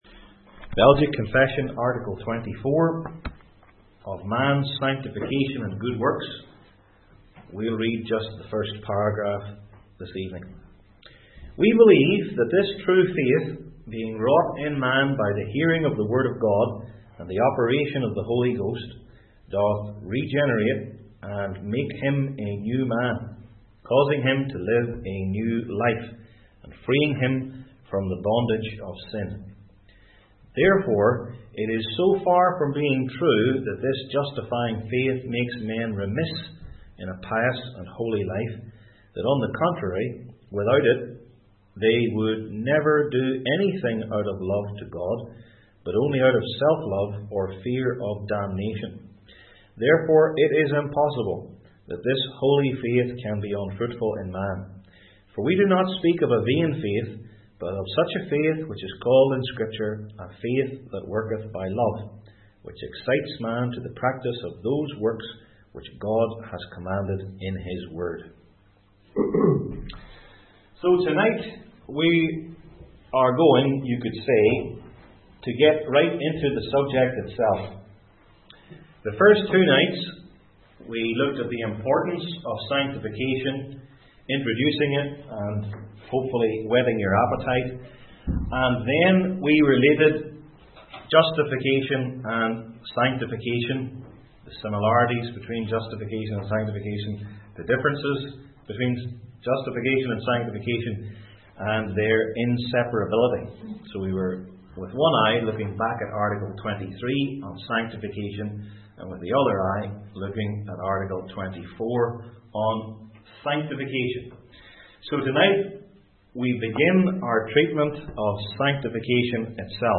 Passage: Ephesians 2:1-10 Service Type: Belgic Confession Classes